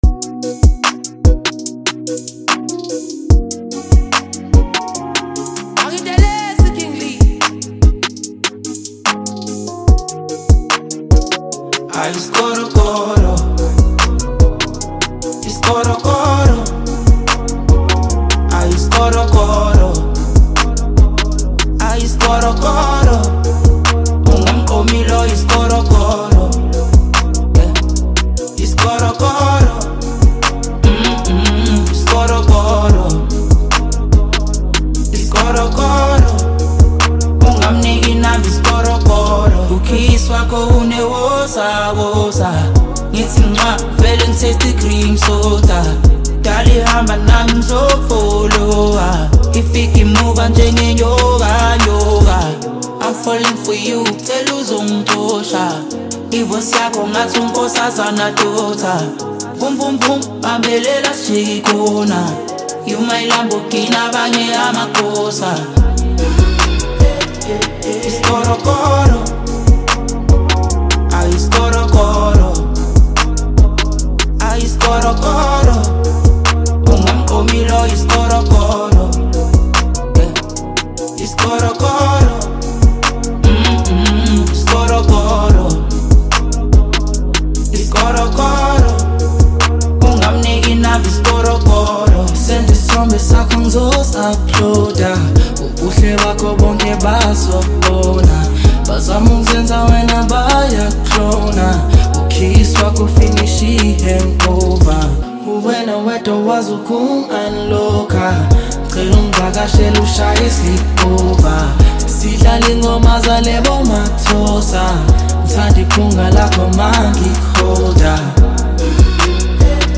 vibrant rhythms
smooth sound, balanced mood